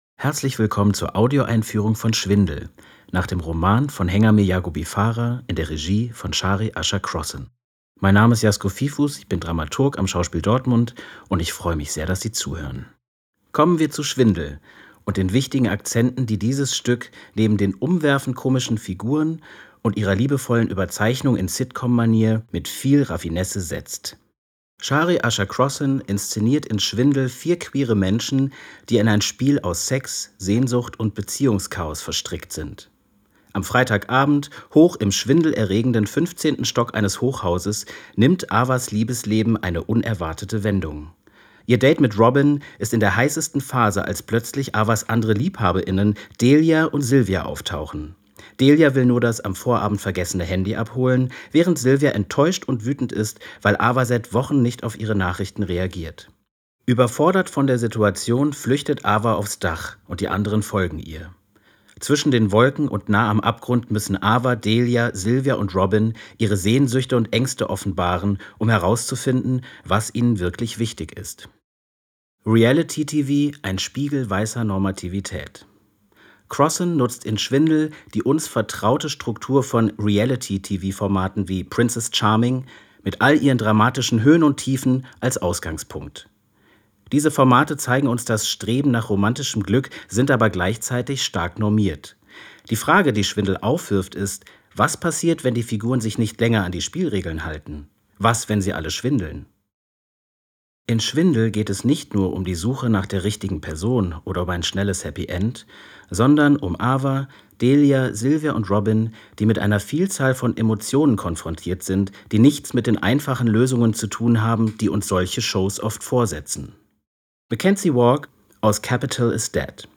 tdo_einfuehrung_schwindel.mp3